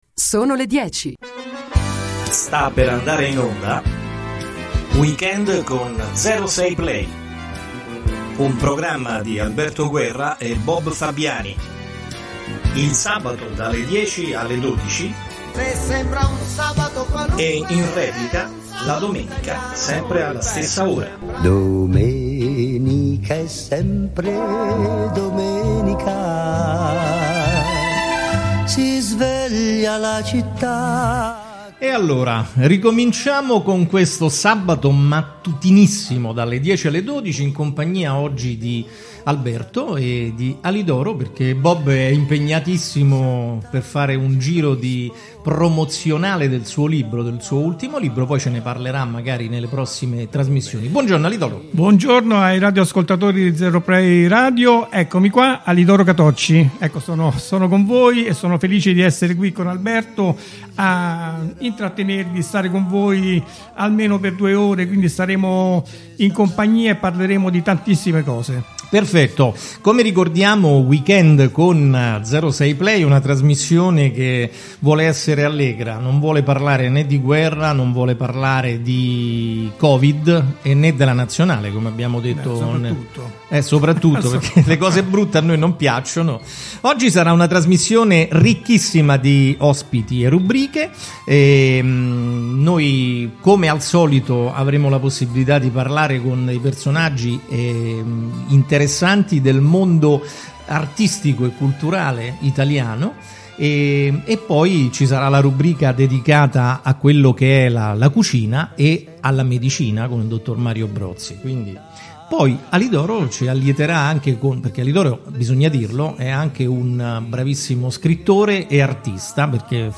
Tutti i sabati e in replica la domenica dalle 10 alle 12 la trasmissione di 06play che vuole parlare solo di cose allegre e farvi ascoltare tanta bella musica. Il tutto condito da interviste e rubriche.